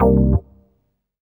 ORGAN-06.wav